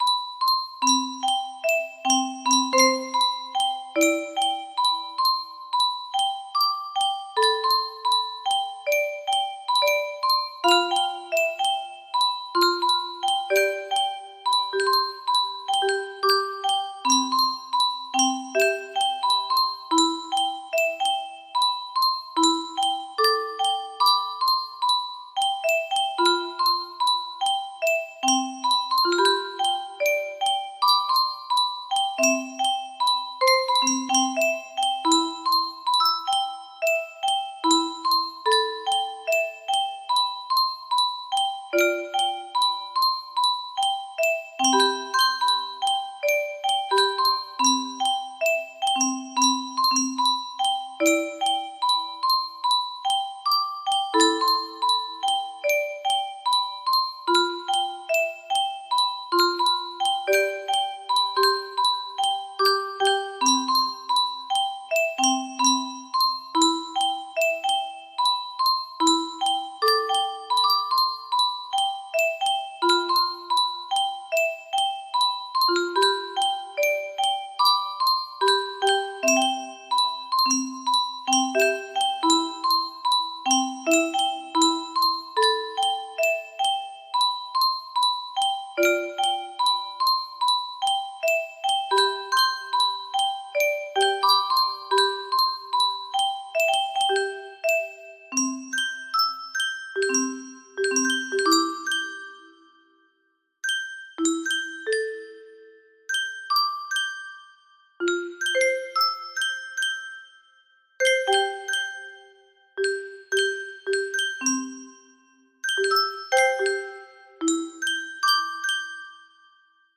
adapted for 20 Notes Muro Box